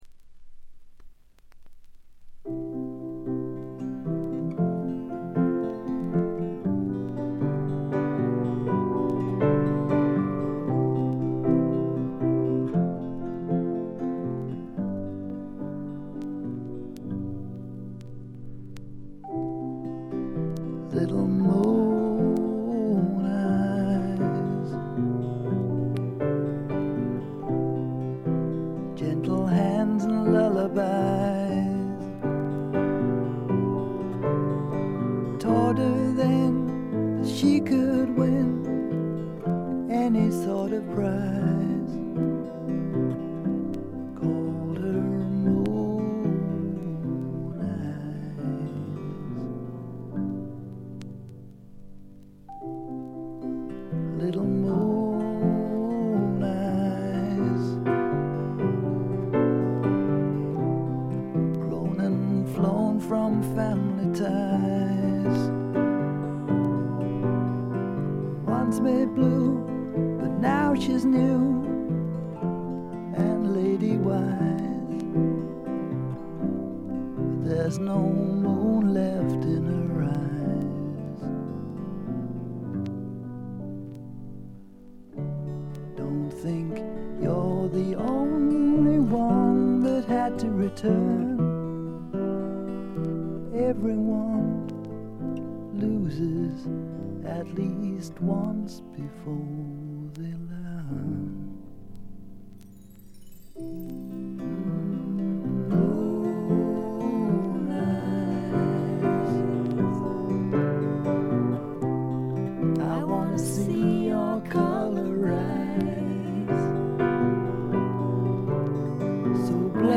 ホーム > レコード：英国 SSW / フォークロック
A1序盤とB1冒頭でチリプチがやや目立ちますが、これ以外は静音部での軽微なノイズ程度。
試聴曲は現品からの取り込み音源です。